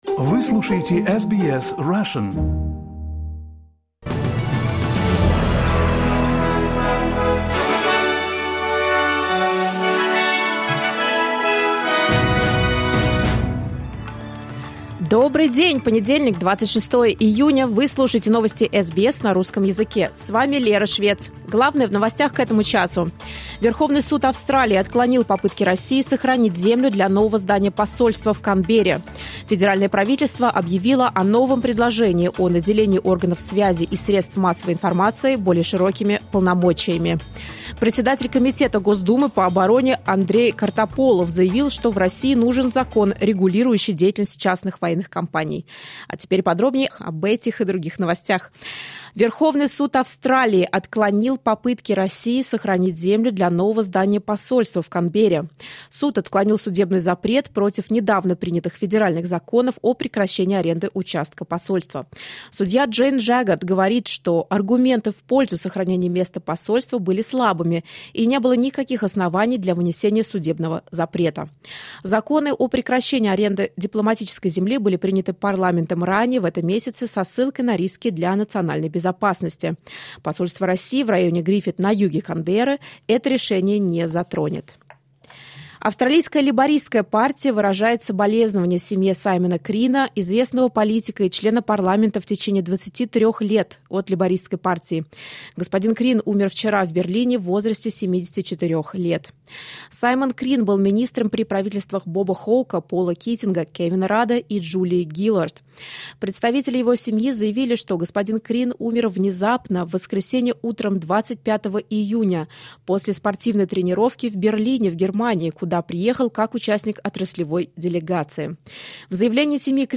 SBS news in Russian — 26.06.2023